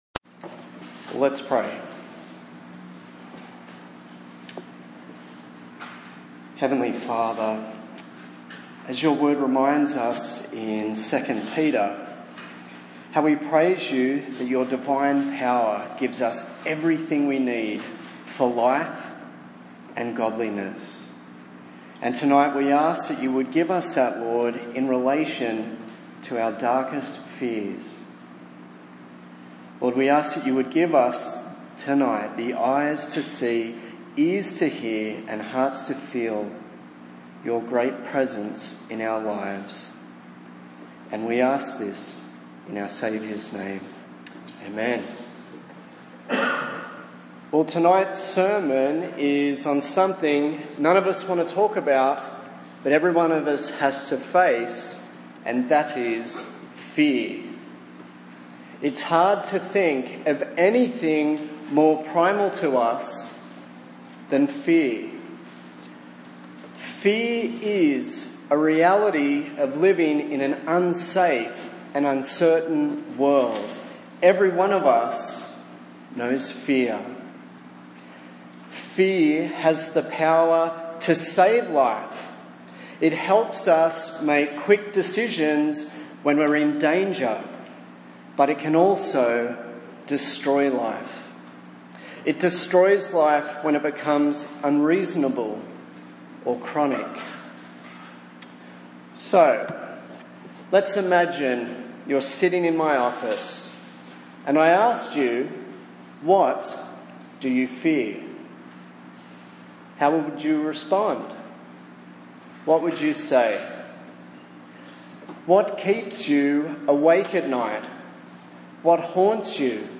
A sermon on the book of Isaiah